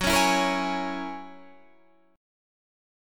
Listen to F#9 strummed